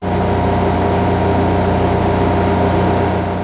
Descarga de Sonidos mp3 Gratis: generador 1.
electronics013.mp3